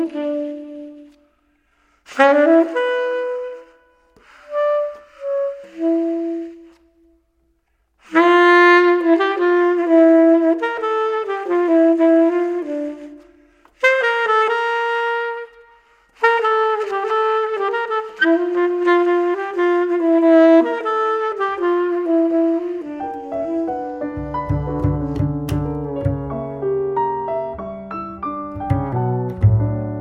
saxo et clar.